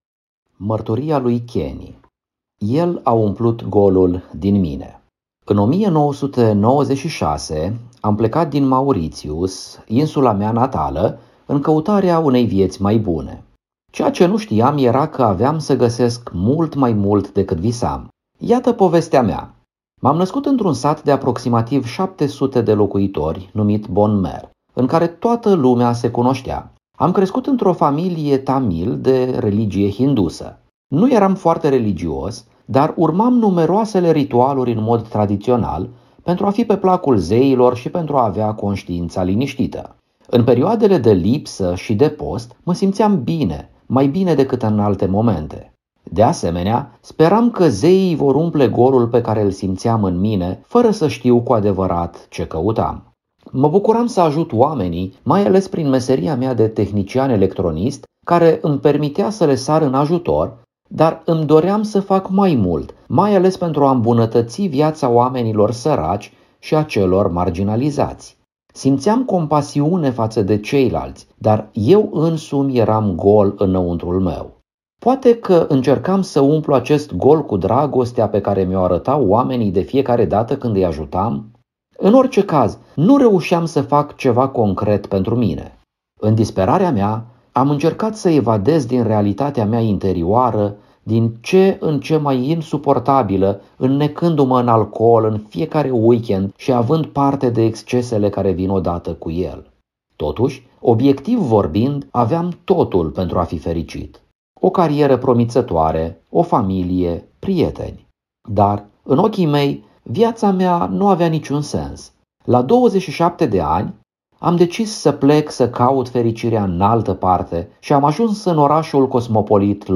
Audio, Mărturii